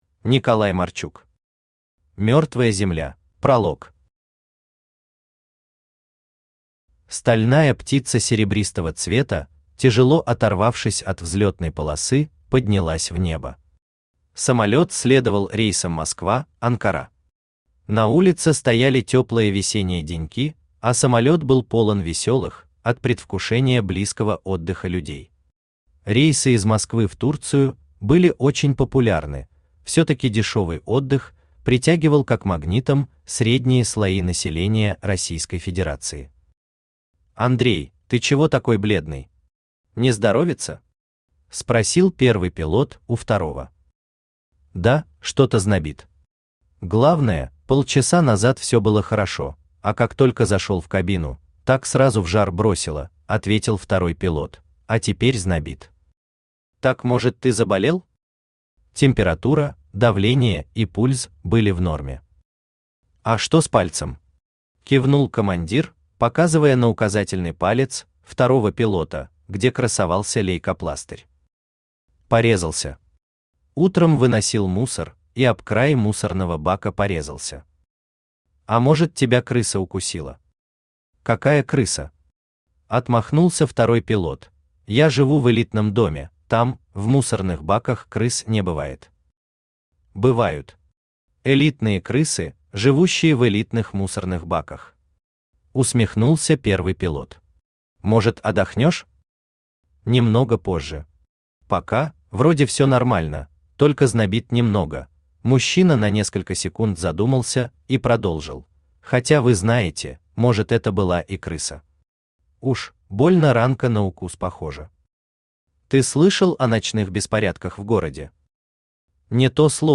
Aудиокнига Мертвая земля Автор Николай Марчук Читает аудиокнигу Авточтец ЛитРес.